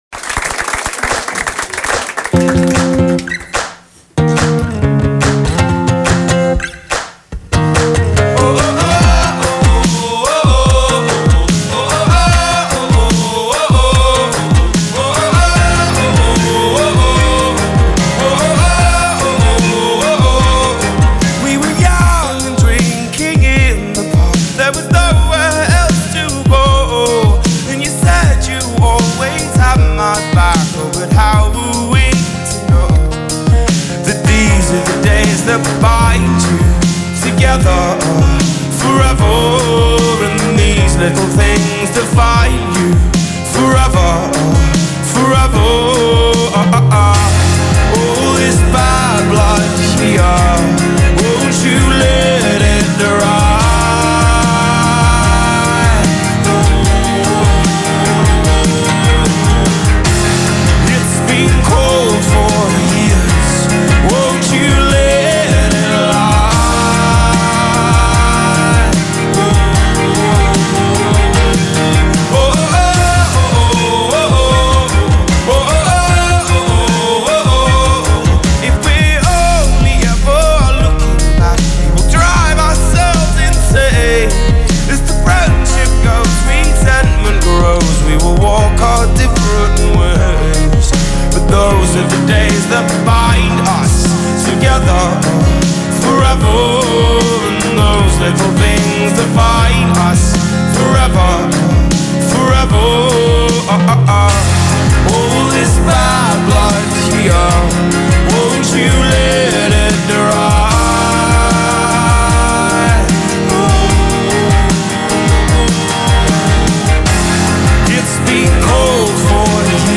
Alternative band